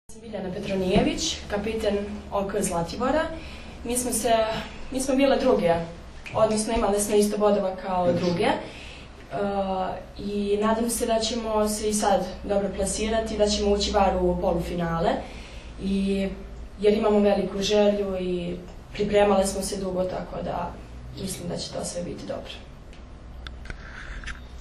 Na Zlatiboru startovala “Kids liga” i “Vip Beach Masters škole odbojke” – održana konferencija za novinare
IZJAVA